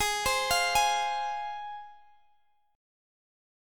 Listen to G#6 strummed